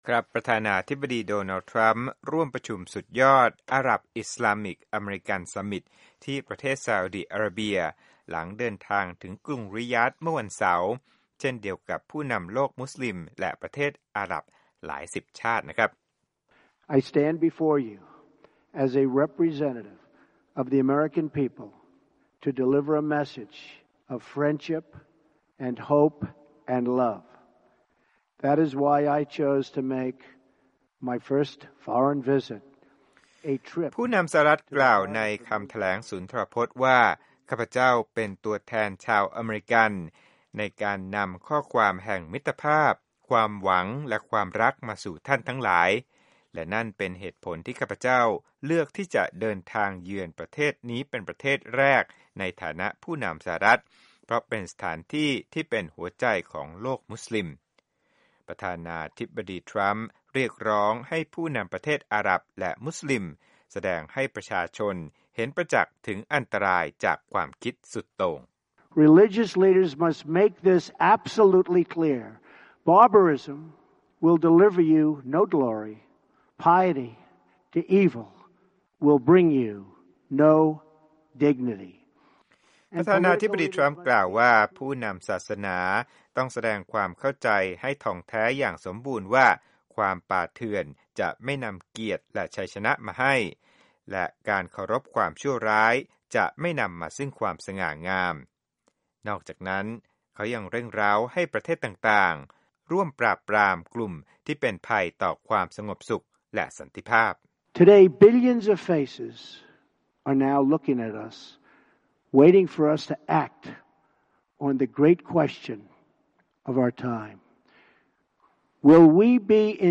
President Donald Trump delivers a speech to the Arab Islamic American Summit, at the King Abdulaziz Conference Center, May 21, 2017, in Riyadh, Saudi Arabia.